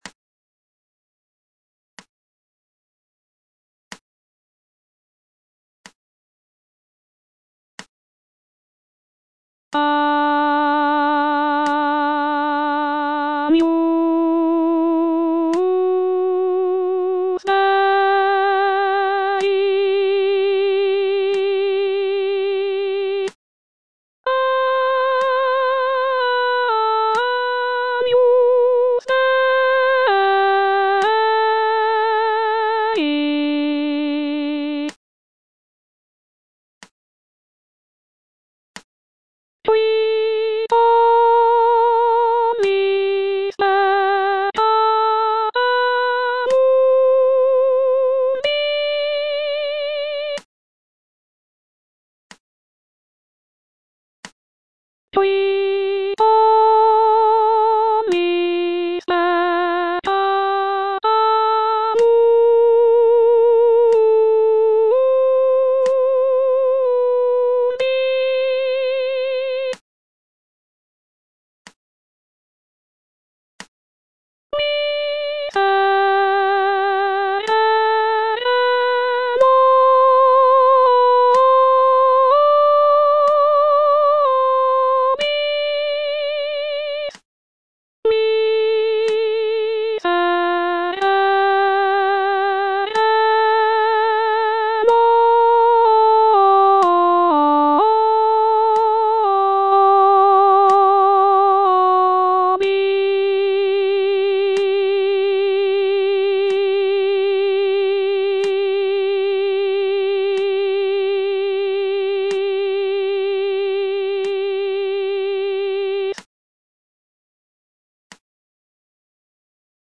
T.L. DE VICTORIA - MISSA "O MAGNUM MYSTERIUM" Agnus Dei (soprano II) (Voice with metronome) Ads stop: auto-stop
It is renowned for its rich harmonies, expressive melodies, and intricate counterpoint.